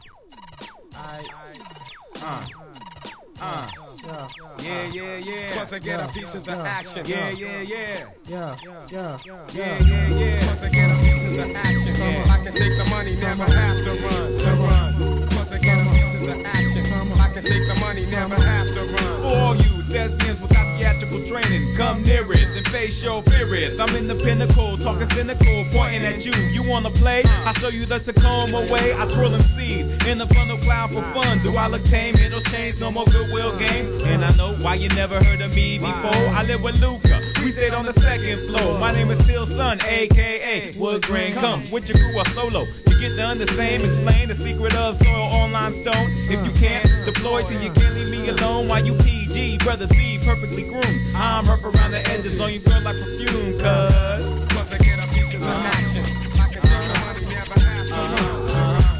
HIP HOP/R&B
ワシントン発良質HIP HOP!!